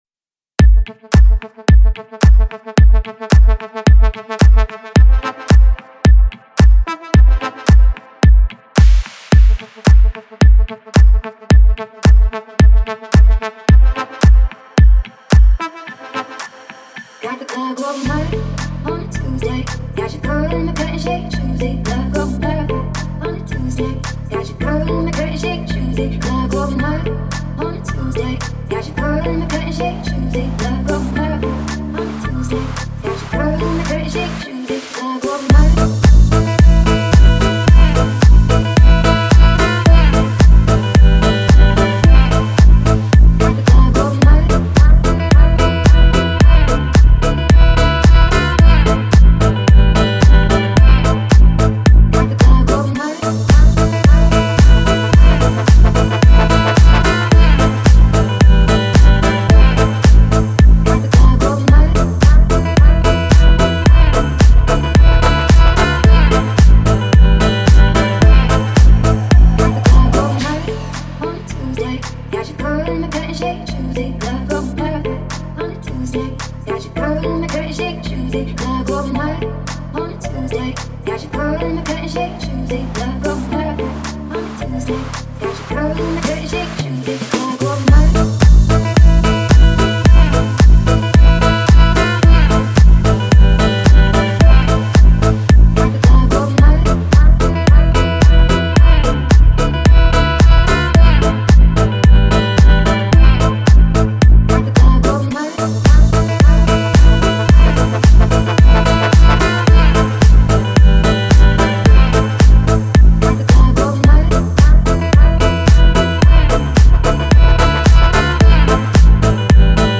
Главная » Файлы » Dance